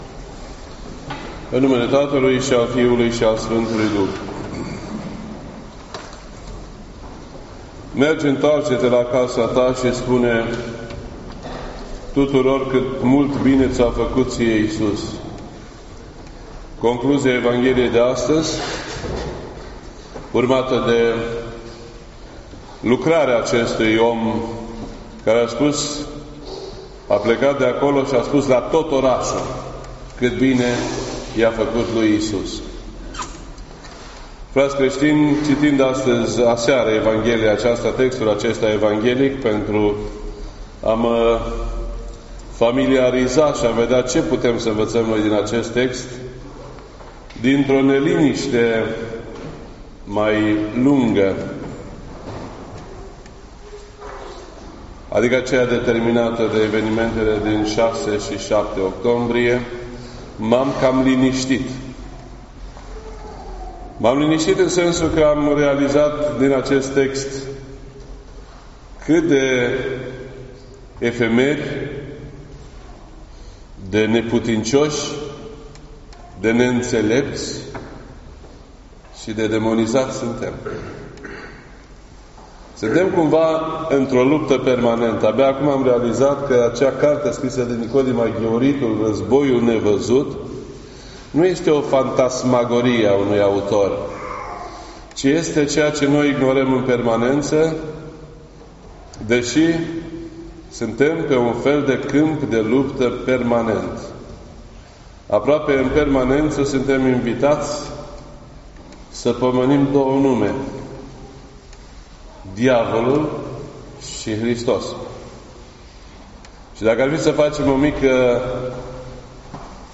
This entry was posted on Sunday, October 21st, 2018 at 12:47 PM and is filed under Predici ortodoxe in format audio.